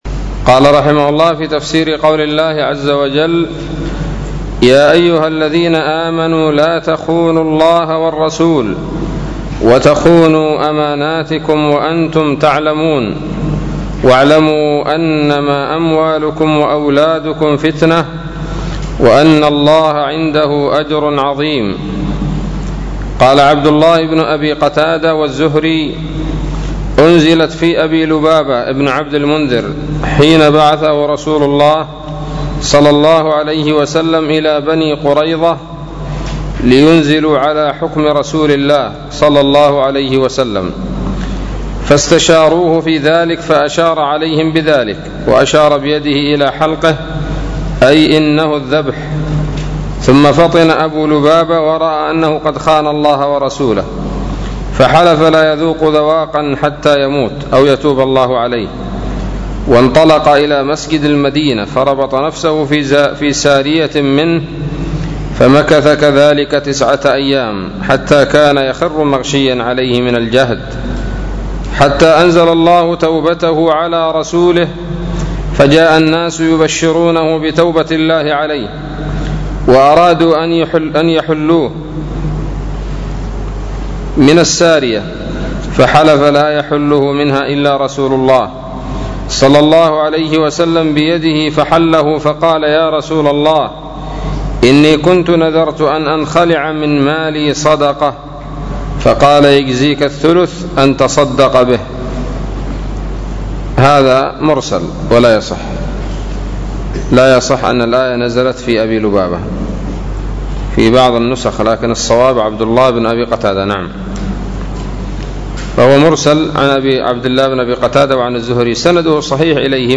الدرس الخامس عشر من سورة الأنفال من تفسير ابن كثير رحمه الله تعالى